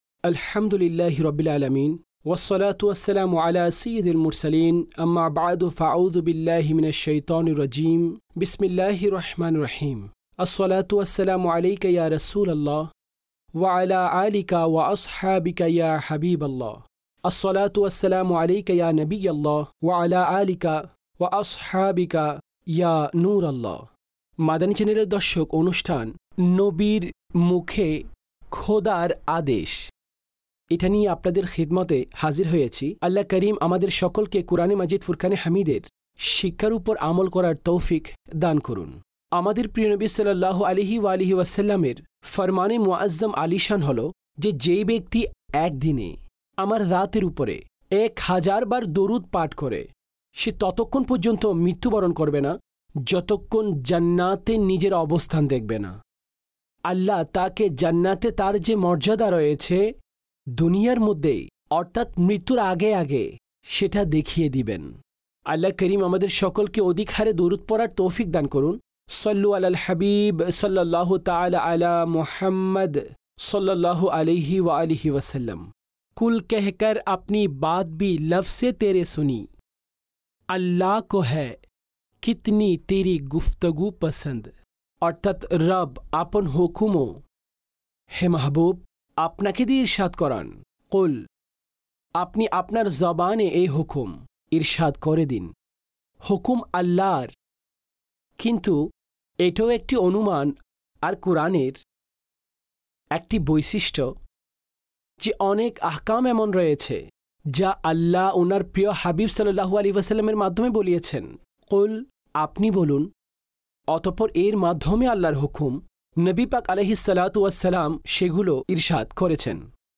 নবী صلی اللہ علیہ وآلہ وسلم 'র মুখে খোদার আদেশ (বাংলায় ডাবিংকৃত)